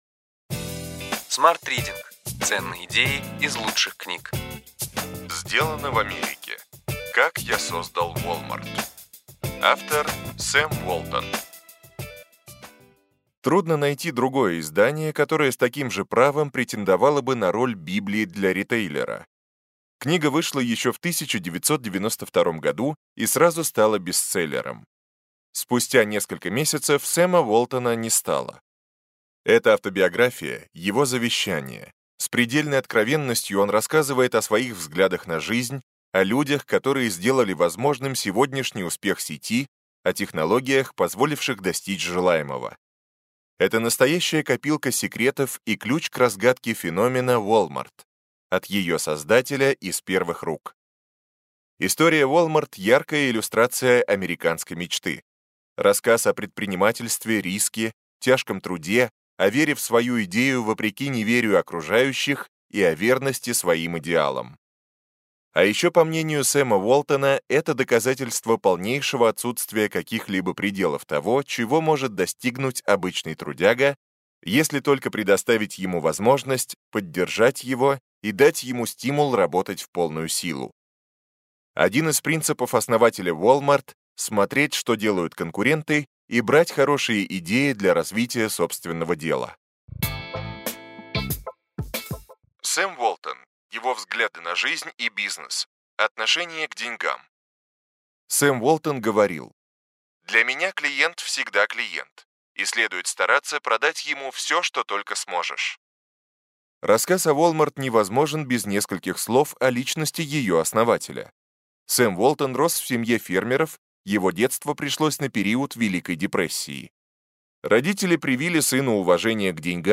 Аудиокнига Ключевые идеи книги: Сделано в Америке. Как я создал Wal-Mart. Сэм Уолтон | Библиотека аудиокниг